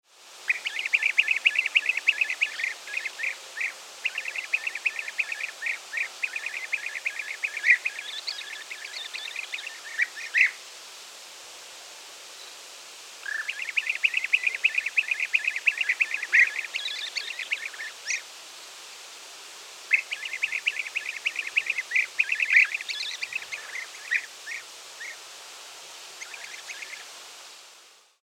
7Bienenfresser, ein bunter Vogel
Sein großes Hobby ist es, das auch mit einem lautstarken „pitt-pitt-pitt“ kund zu tun.
1731_Bienenfresser_Fuetterung_short.mp3